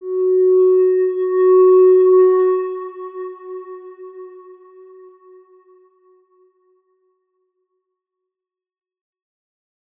X_Windwistle-F#3-mf.wav